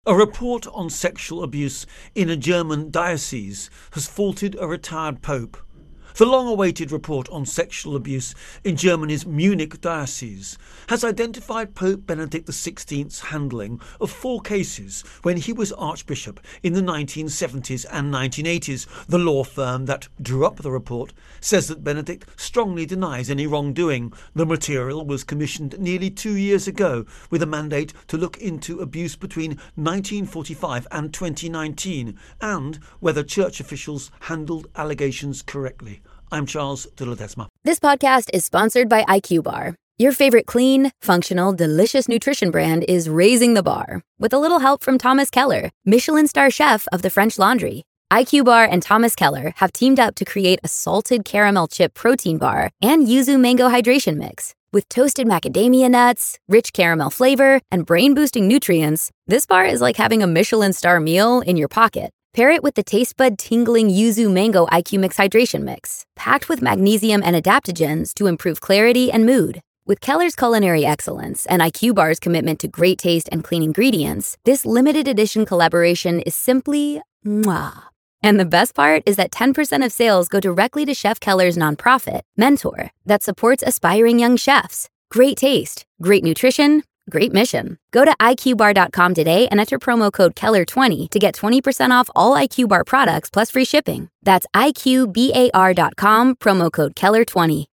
Germany-Church Abuse Intro and Voicer on